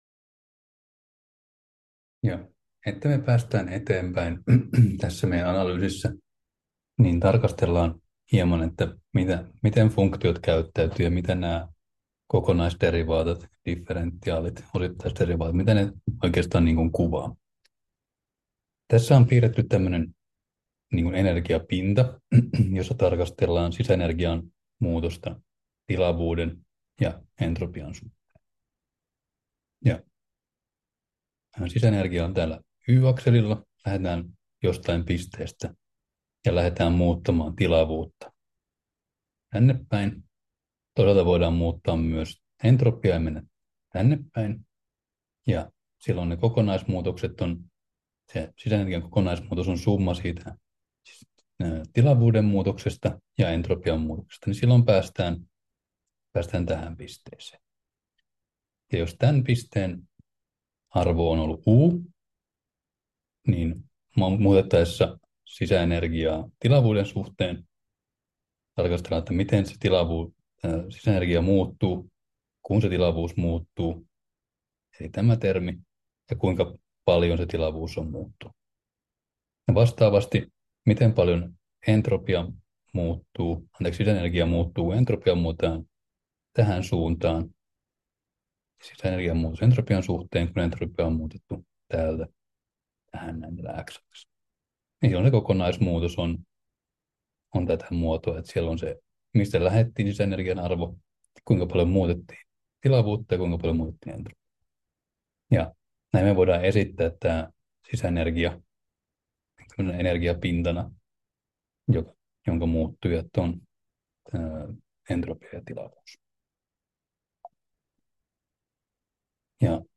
Luento 6: Vapaa energia 2 — Moniviestin